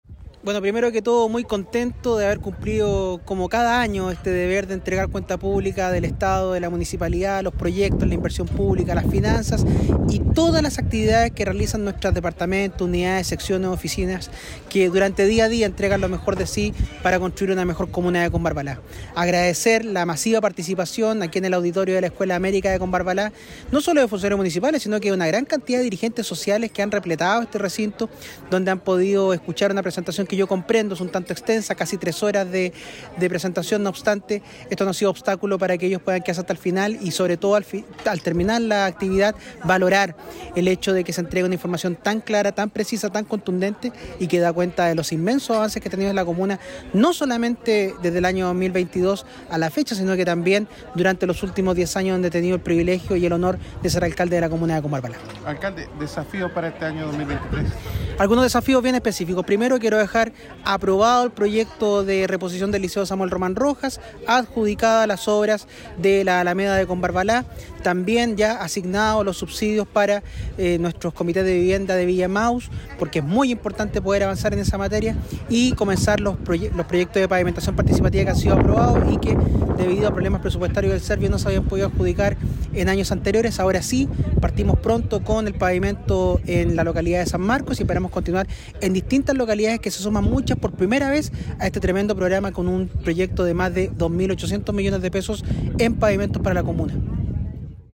Alcalde-de-Combarbala-Pedro-Castillo-Diaz.mp3